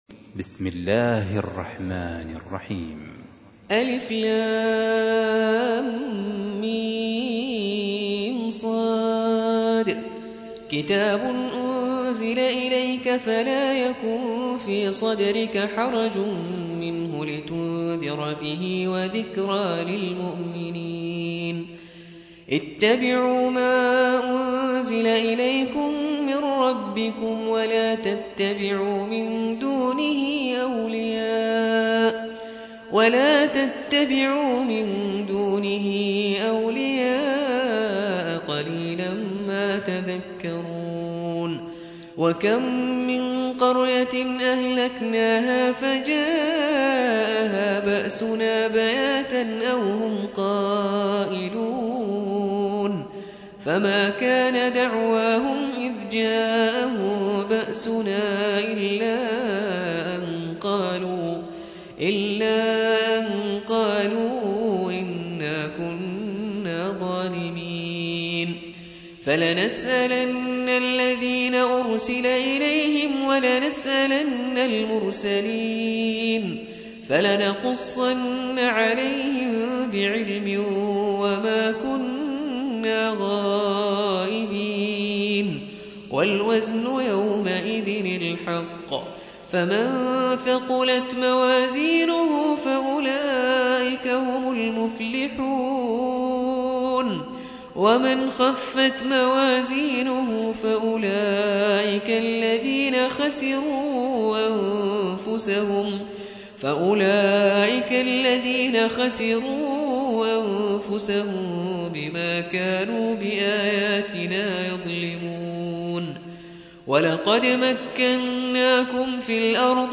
7. سورة الأعراف / القارئ